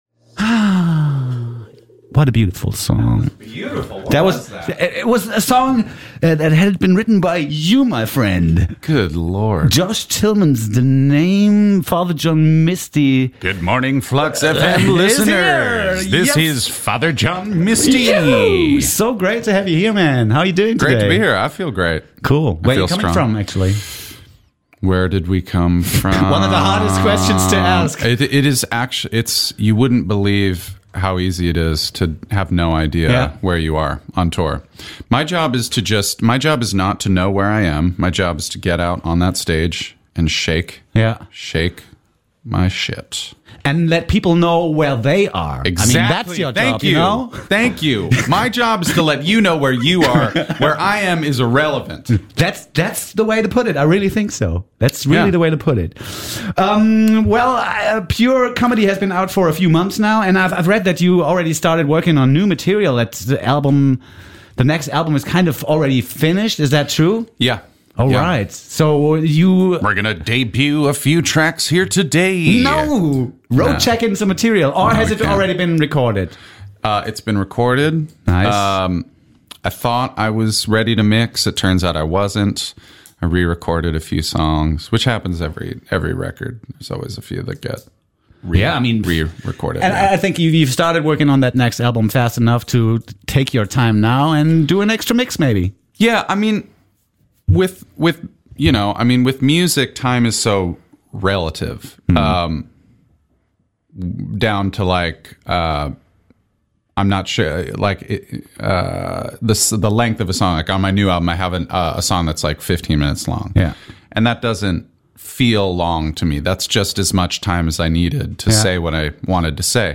Anti-Folk-Schwergewicht Father John Misty aka Josh Tillmann, ehemaliger Schlagzeuger der Fleet Foxes, besucht unser Kreuzberger Studio. Denn Dienstagabend präsentiert er sein neues Album Pure Comedy. Im Radio verlost er die allerletzten Tickets für das ausverkaufte Huxleys.